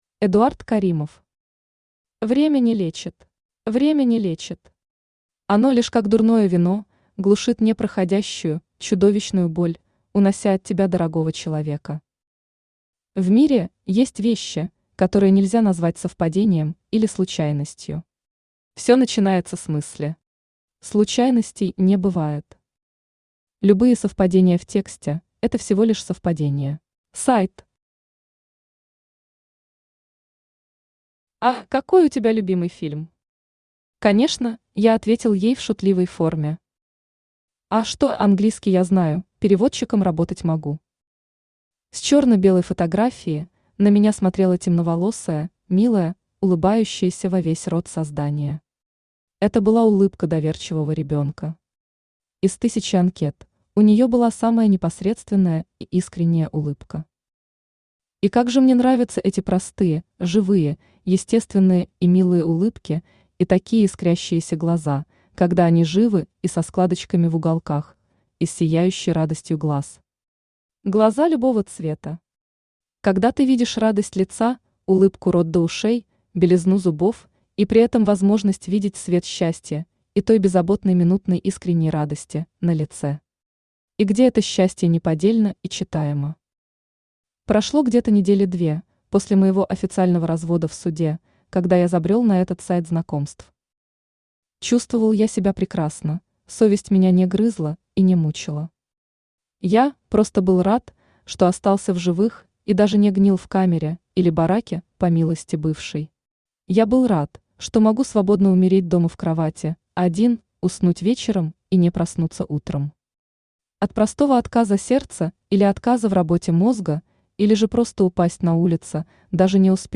Аудиокнига Время не лечит | Библиотека аудиокниг
Aудиокнига Время не лечит Автор Эдуард Рафаильевич Каримов Читает аудиокнигу Авточтец ЛитРес.